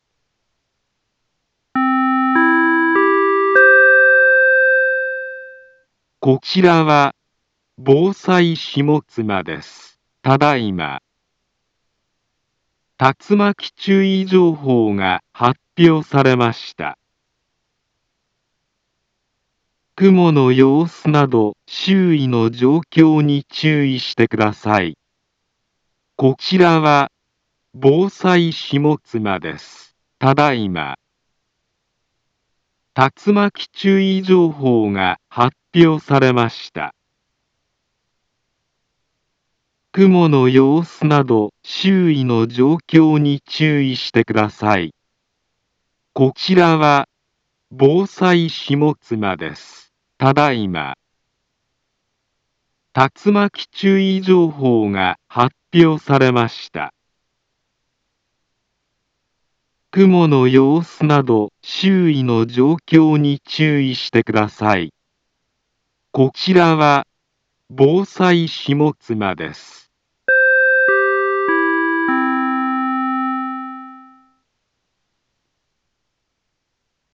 Back Home Ｊアラート情報 音声放送 再生 災害情報 カテゴリ：J-ALERT 登録日時：2021-07-10 20:05:10 インフォメーション：茨城県南部は、竜巻などの激しい突風が発生しやすい気象状況になっています。